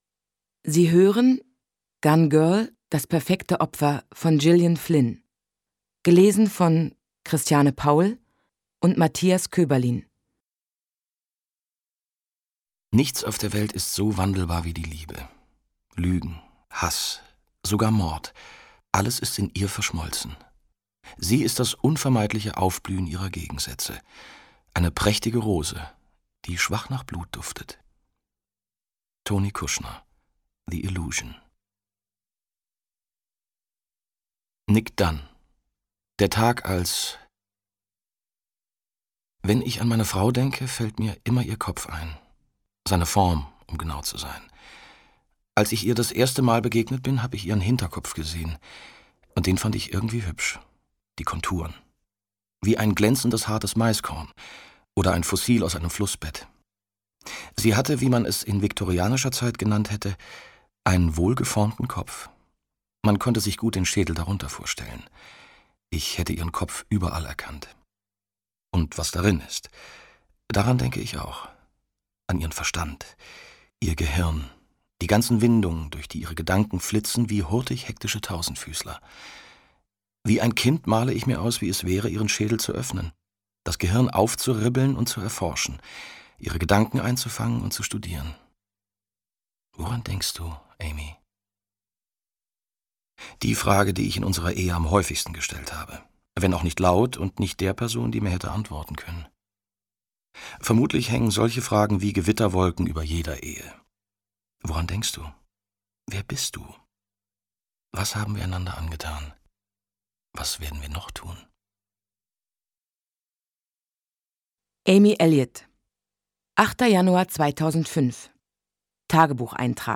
Christiane Paul, Matthias Koeberlin (Sprecher)
Christiane Paul und Matthias Koeberlin kennen alle Sprechertricks, um Gillian Flynns perfides Beziehungsspiel zu einem unerträglich spannenden Hörbuch zu machen.
Mit ihrer klaren, spielfreudigen Stimme hat sie bereits in so unterschiedlichen Hörbüchern wie Alice im Wunderland oder Morpheus von Jilliane Hoffman geglänzt.
Als Hörbuch-Sprecher versteht er es, sowohl spannungsgeladenen als auch gefühlsbetonten Stoffen den richtigen Schwung zu geben.